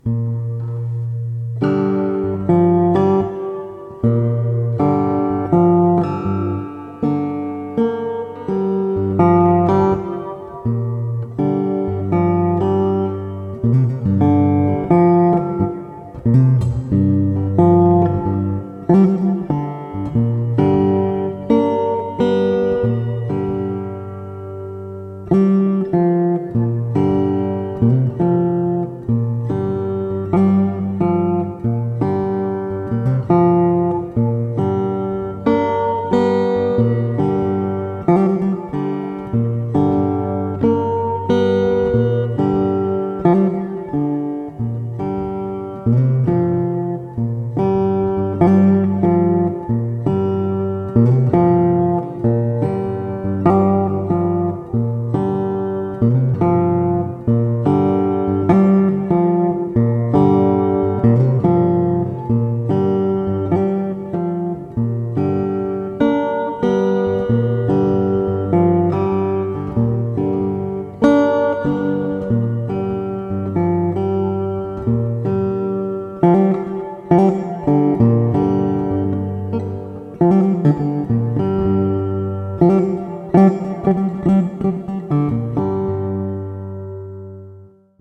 Slow Delta Blues Dobro Guitar.